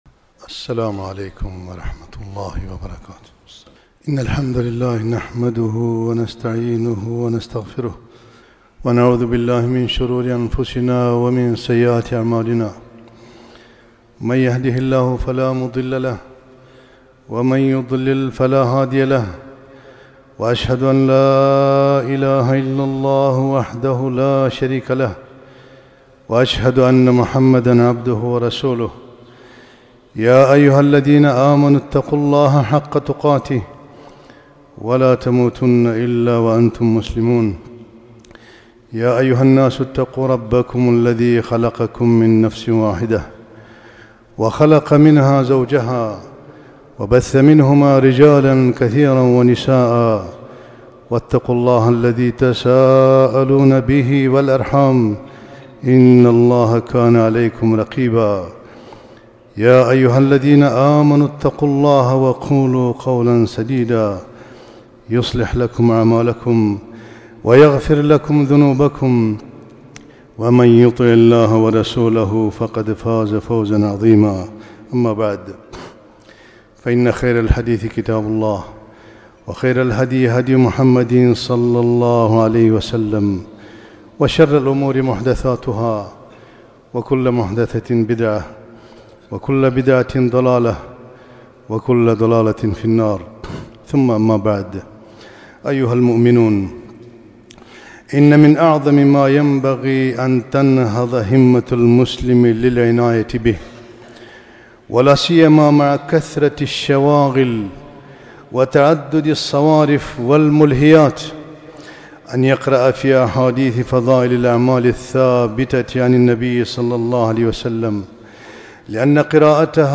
خطبة - الطهور شطر الإيمان